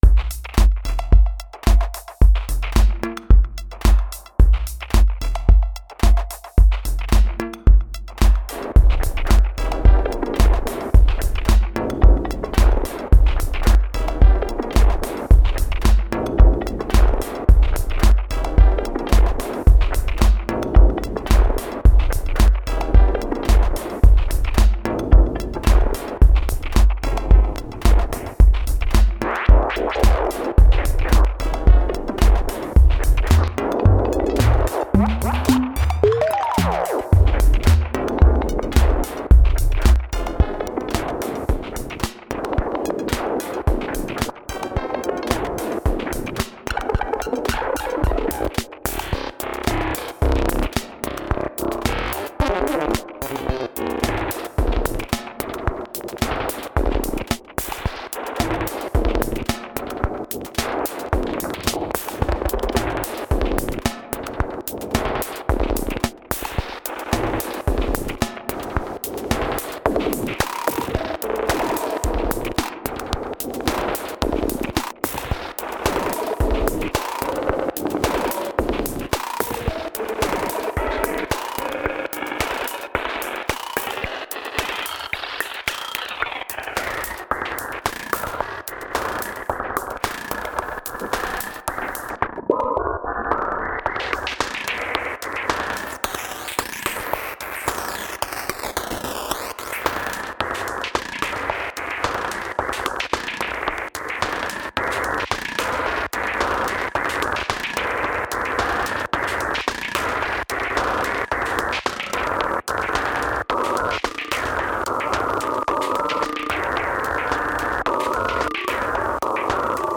)) В демке сначала просто играют разные синтезированные ударные, потом, на восьмой секунде, на одной дорожке включаются рекордер с хаотично расставленными рекорд-триггерами, который записывает всё. А на другой дорожке включается рекорд-плеер, у которого тоже случайно разбросаны триггеры, который проигрывает записанное рекордером. То есть получается такой хаотичный овердабинг с фидбэком.
)) Думаю, ни одна драммашина не способна дать хоть что-то подобное )) а на компе это потребовало бы кучу телодвижений, а тут всё удобно, быстро и интересно )) Только синтез, без использования сэмплов, не считая внутреннего ресэмплинга в реальном времени. В файле микс из мастераутов машиндрама, без доп. обработки.